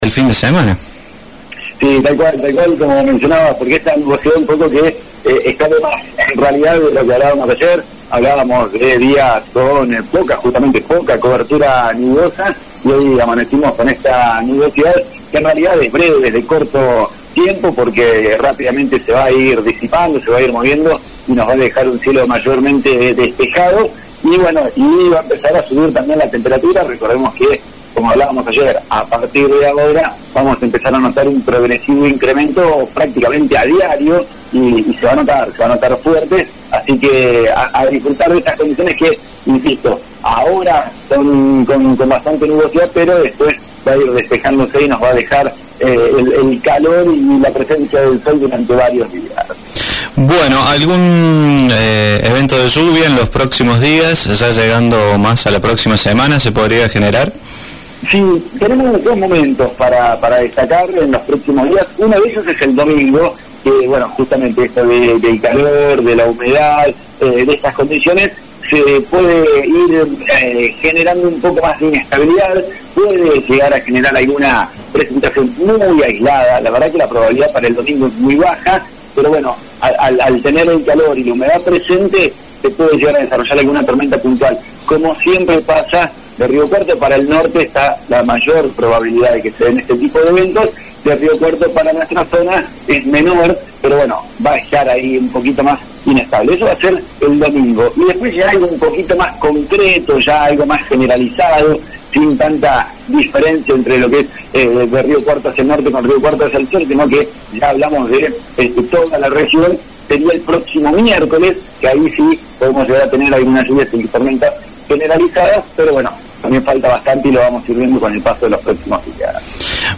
el pronóstico del tiempo para la jornada de hoy